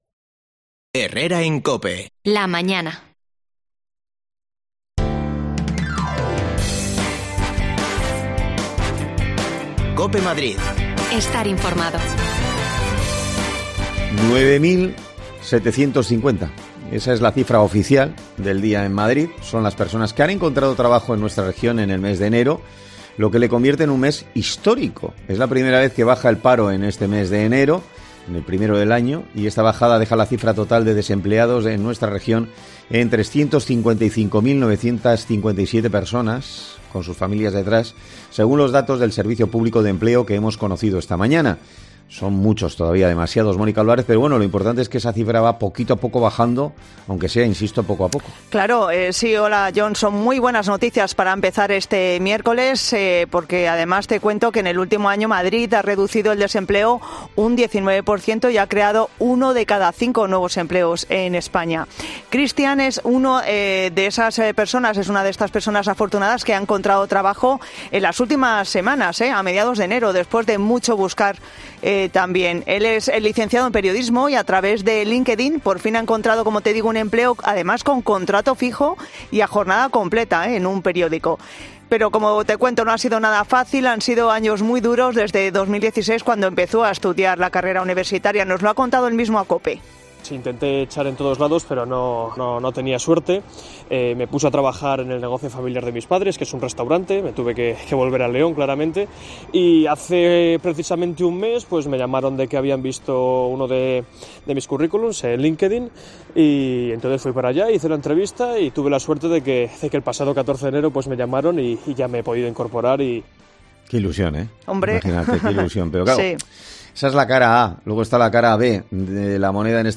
Hablamos con protagonistas
Las desconexiones locales de Madrid son espacios de 10 minutos de duración que se emiten en COPE , de lunes a viernes.